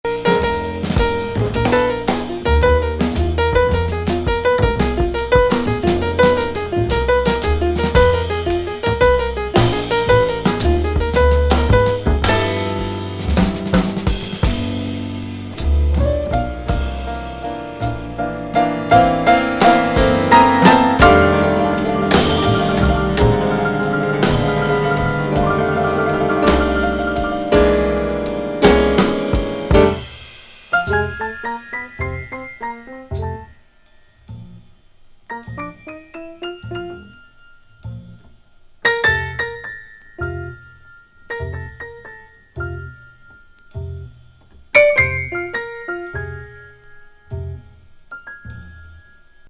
漂う黒いブルーズ感覚。